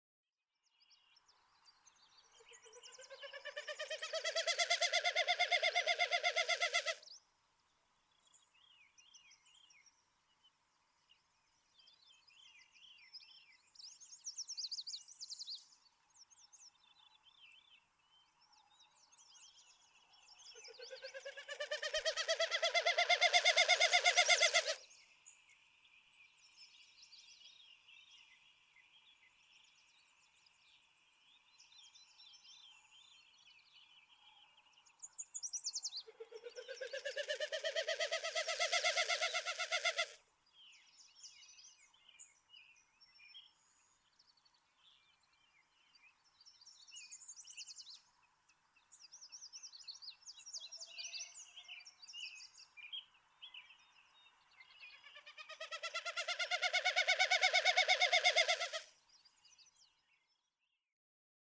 Wilson's snipe
♫68. Winnows, sounding like wuwuwuwuwuwuwuwuwuwuwuwuwuwuwuwu, each wu the result of one wing stroke directing air over the stiffened, outer tail feathers.
Big Hole National Battlefield, Wisdom, Montana.
Wilson's snipe Chapter 2: Birds and Their Sounds Subchapter: Mechanical (non-vocal) sounds From page 23 in the book.
068_Wilson's_Snipe.mp3